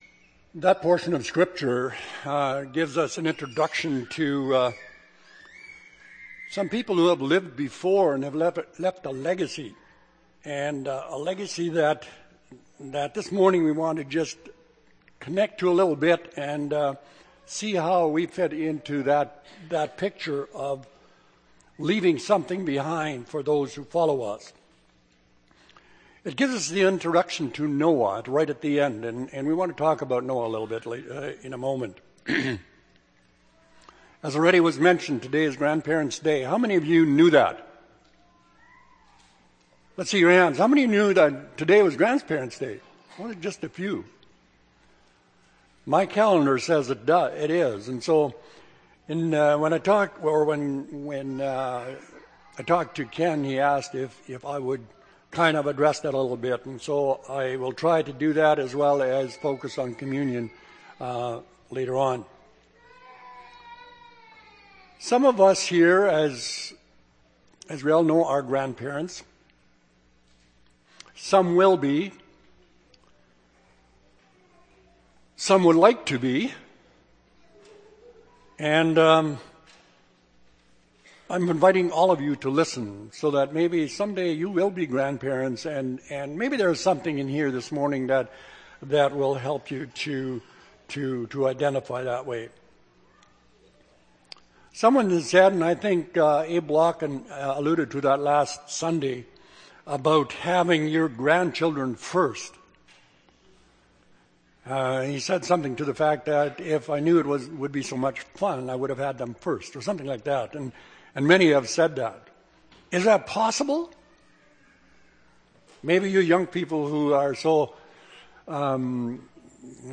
Sept. 30, 2012 – Sermon